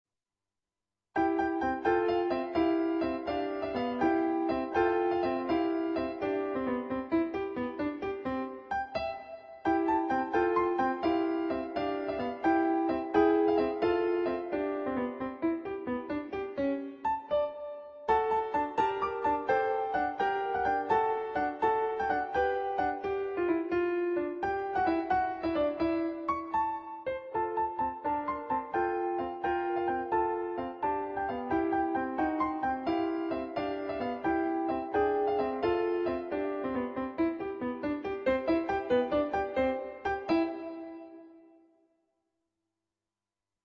on Yamaha digital pianos.